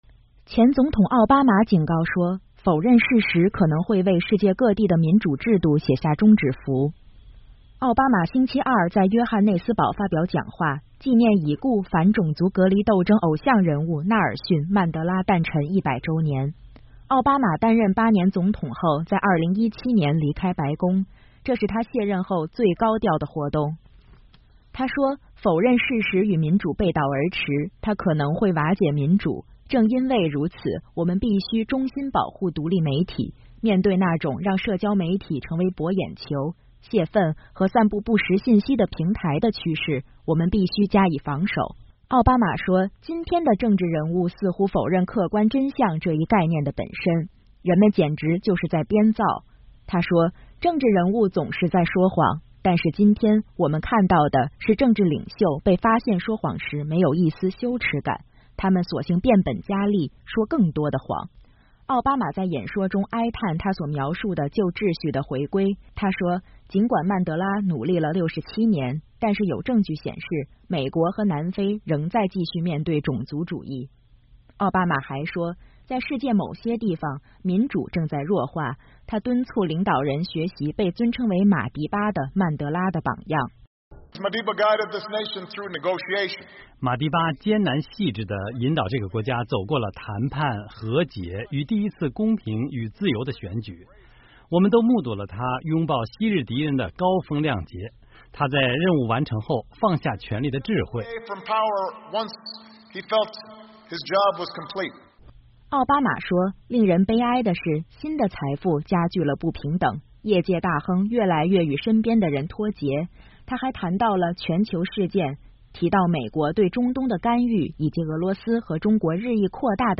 美国前总统奥巴马在南非约翰内斯堡发表《第16届年度纳尔逊·曼德拉演说》。
奥巴马结束演讲时，在场听众报以热烈的掌声和欢呼。